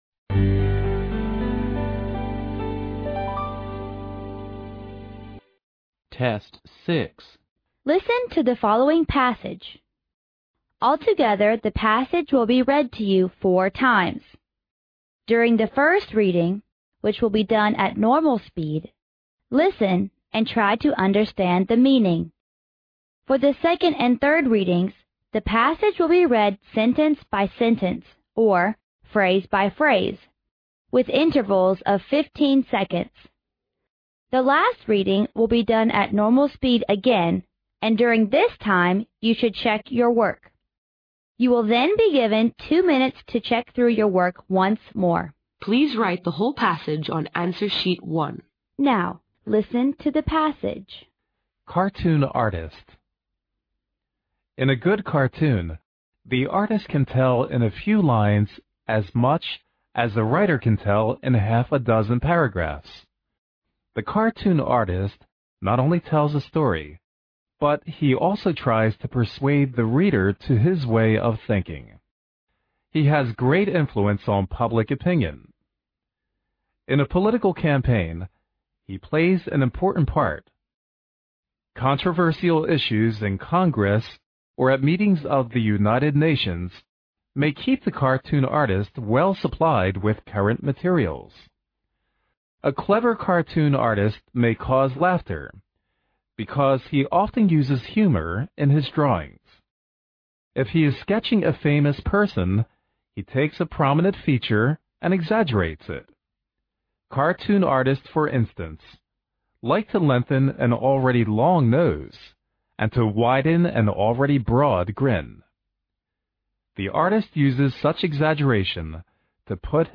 Altogether the passage will be read to you four times.
The last reading will be done at normal speed again and during this time you should check your work.